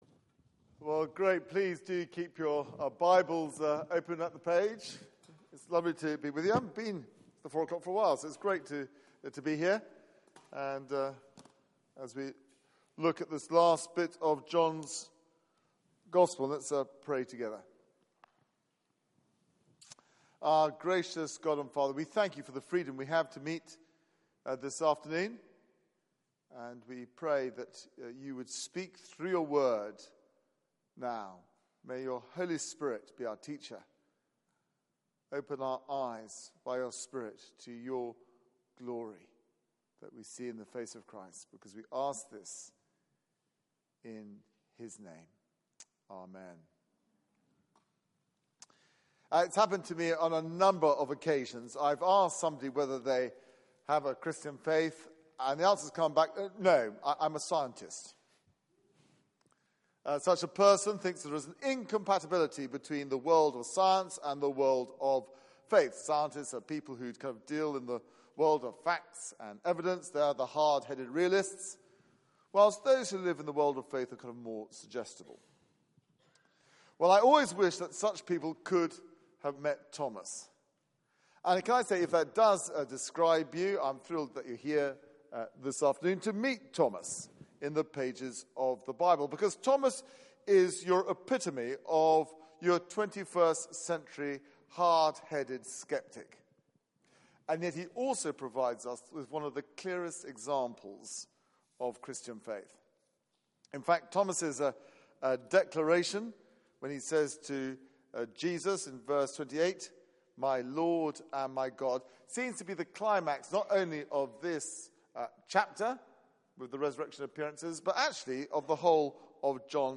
Media for 4pm Service on Sun 03rd Apr 2016 16:00 Speaker